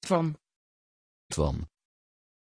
Pronuncia di Toine
pronunciation-toine-nl.mp3